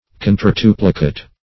Search Result for " contortuplicate" : The Collaborative International Dictionary of English v.0.48: Contortuplicate \Con`tor*tu"pli*cate\, a. [L. contortuplicatus; contortus contorted + plicare to fold.]
contortuplicate.mp3